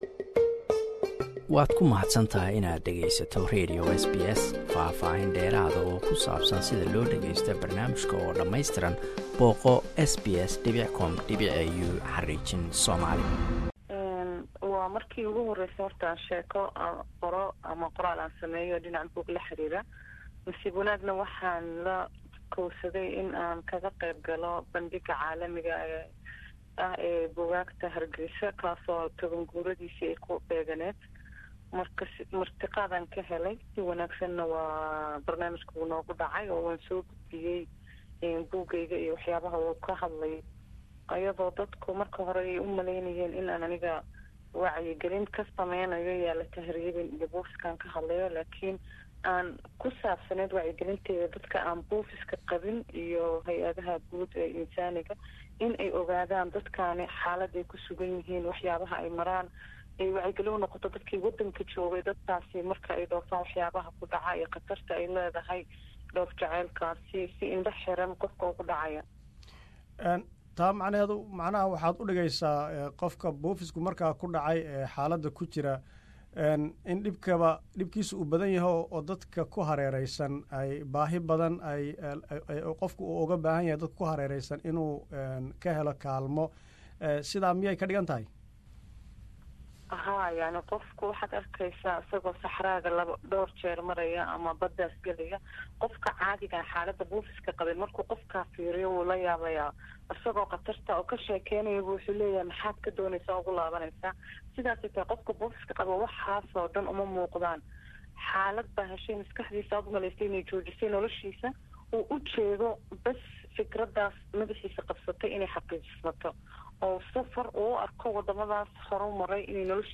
waa riwaayad arimaha buufiska diirada saaraysa